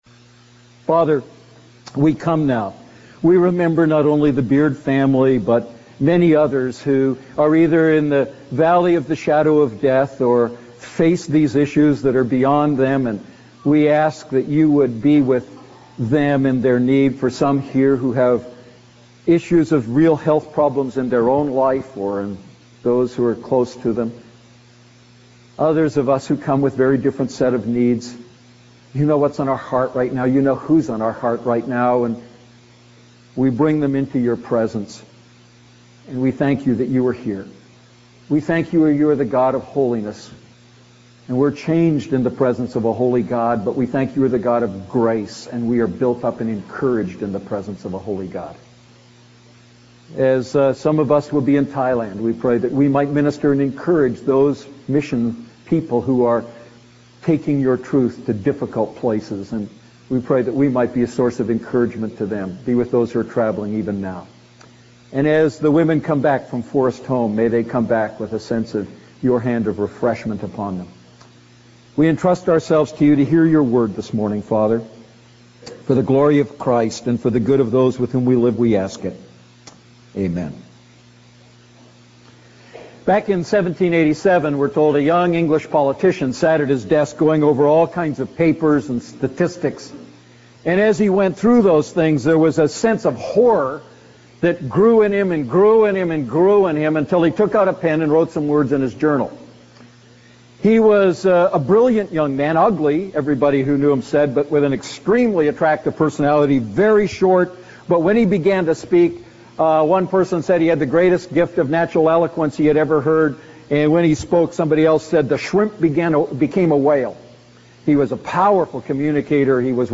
A message from the series "Ephesians Series II."